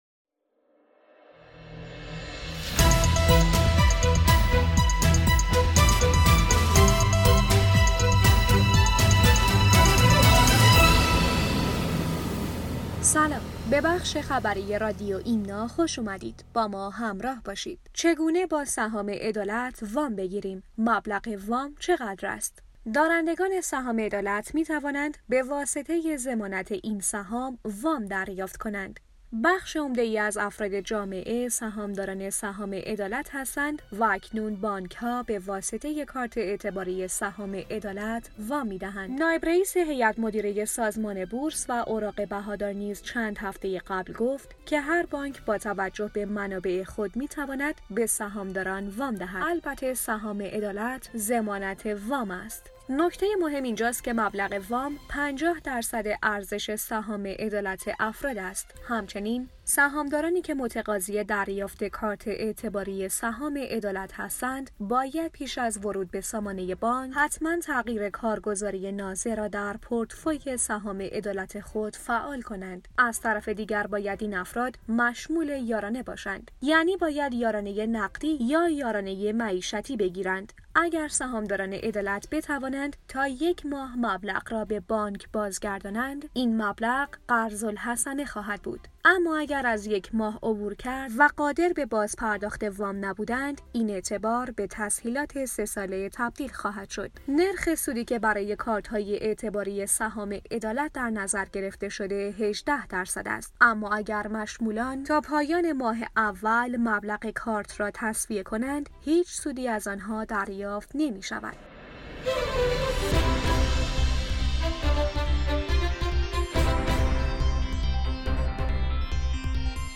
رادیو خبری ایمنا/